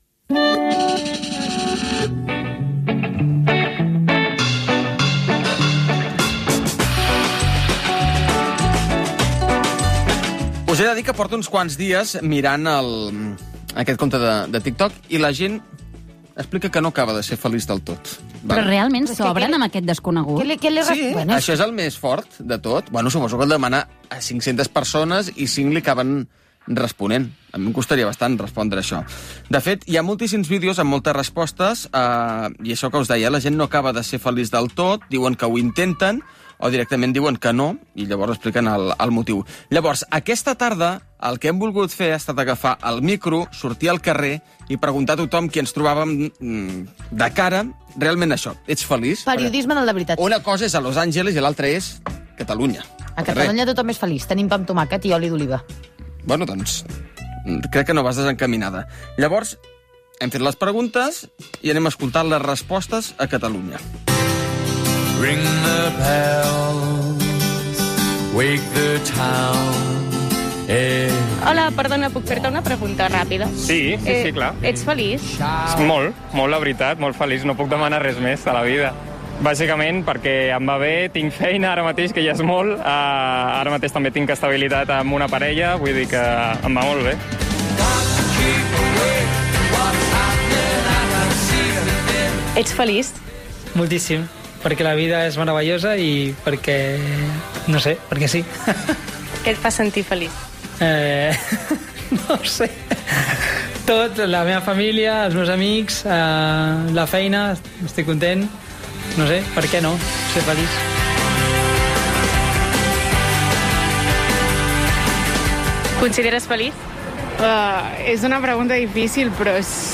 Enquesta sobre si la gent és feliç, comiat, tema musical, identificació del programa, publicitat
Infantil-juvenil